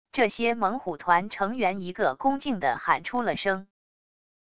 这些猛虎团成员一个恭敬的喊出了声wav音频生成系统WAV Audio Player